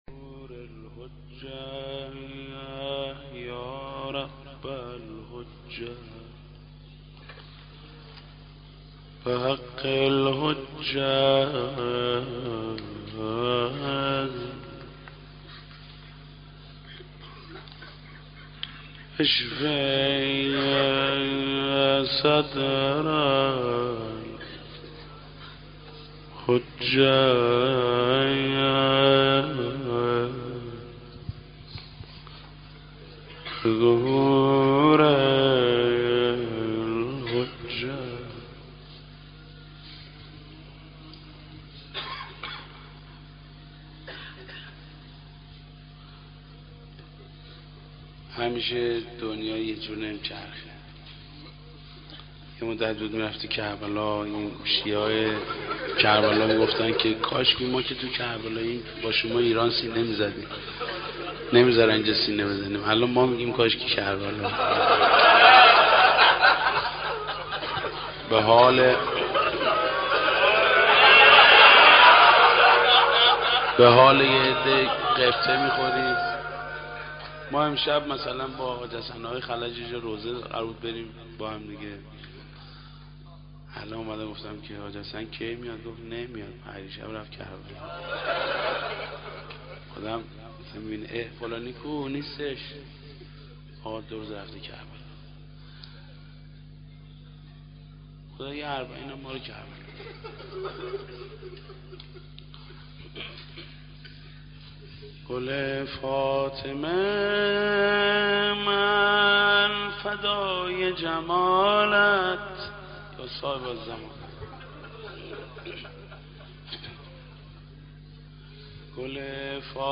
20-Roz Arbaeen{82}Ya Zahra.mp3